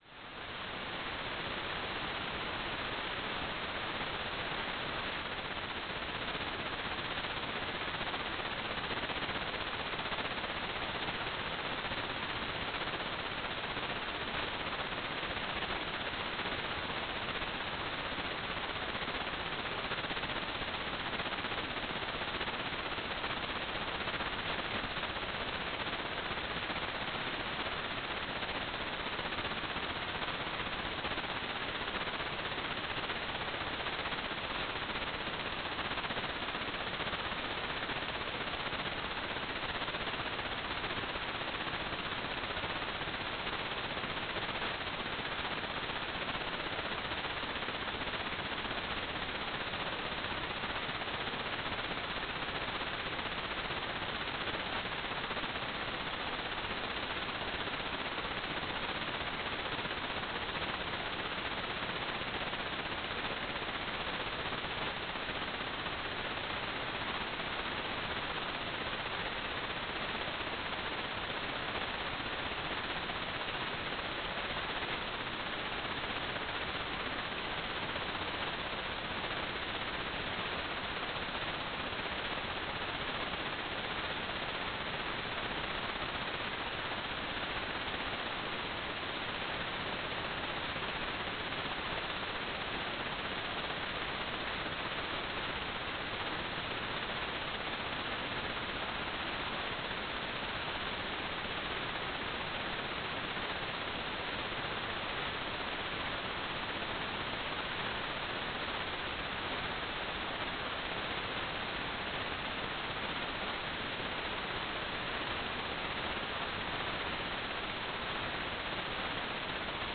"transmitter_description": "Beacon",
"transmitter_mode": "CW",